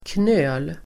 Uttal: [knö:l]